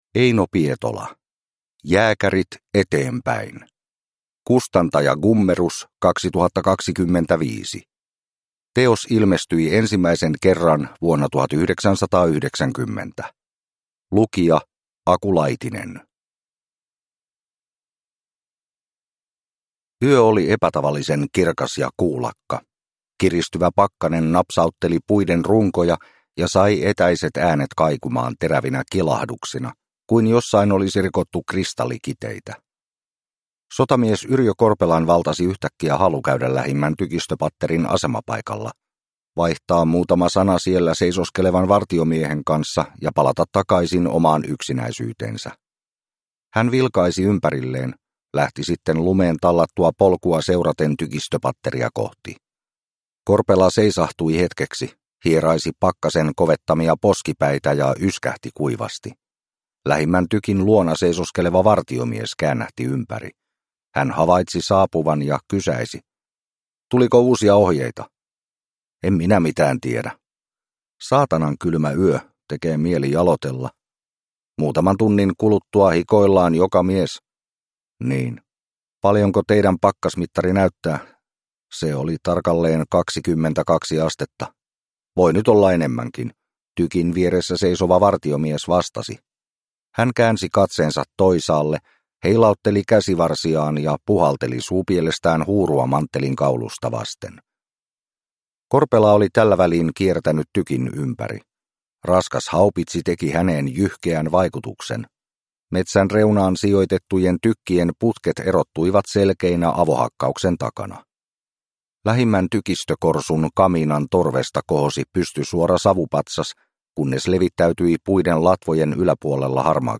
Jääkärit, eteenpäin! (ljudbok) av Eino Pietola